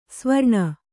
♪ svarṇa